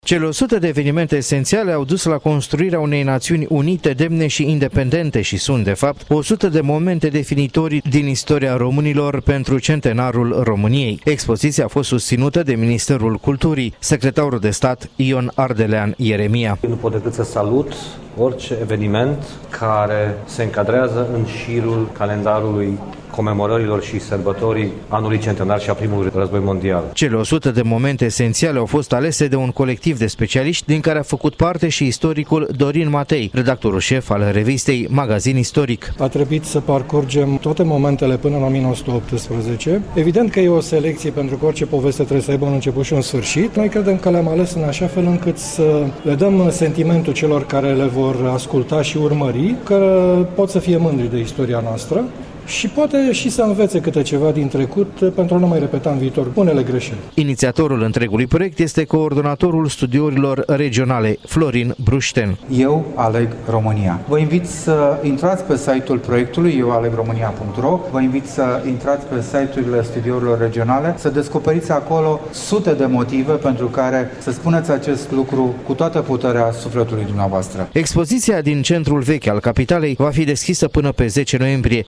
Postul de radio Bucuresti FM, parte a reţelei Radio România Regional, a inaugurat ieri după-amiază, în Centrul vechi al Capitalei, lângă Banca Naţională, expoziţia întitulată „România 100 – Eu aleg România”, în care sunt prezentate o sută de momente esenţiale din istoria românilor. Un reportaj